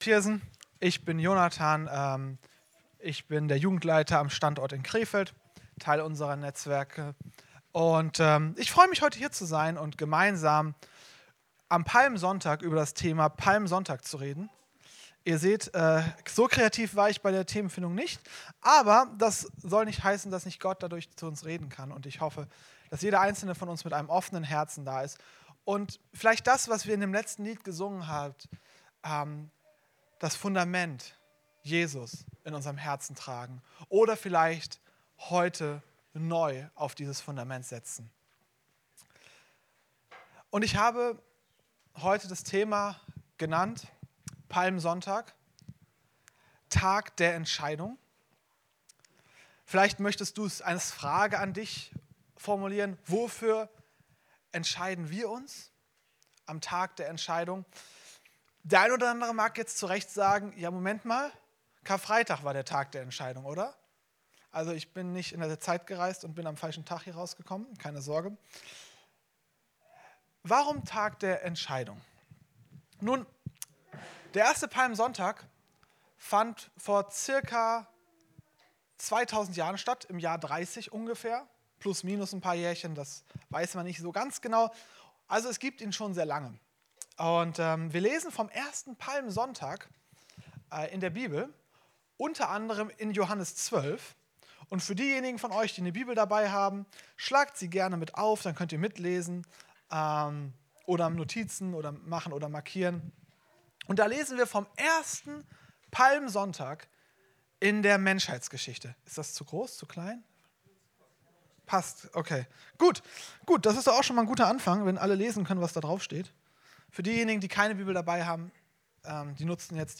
Predigten aus Viersen – Evangeliumshaus